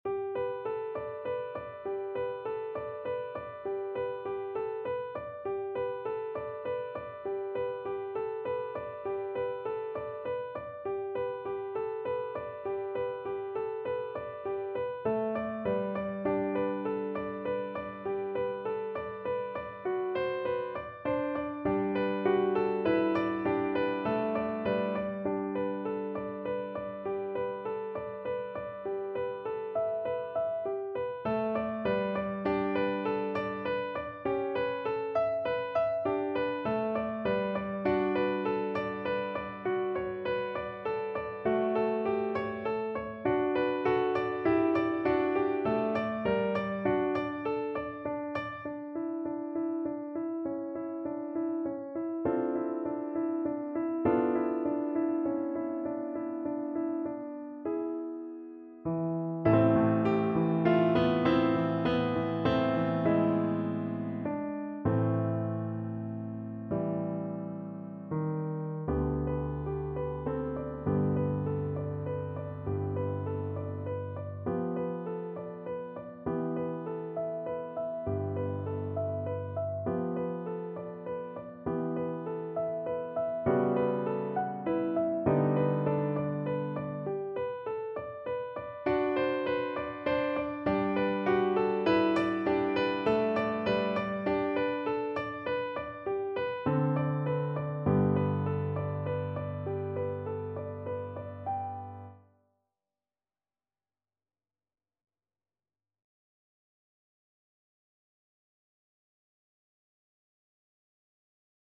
Play (or use space bar on your keyboard) Pause Music Playalong - Piano Accompaniment Playalong Band Accompaniment not yet available reset tempo print settings full screen
~ = 120 Allegretto
G major (Sounding Pitch) (View more G major Music for Violin )
Classical (View more Classical Violin Music)
rachmaninoff_op21_5_lilacs_VLN_kar1.mp3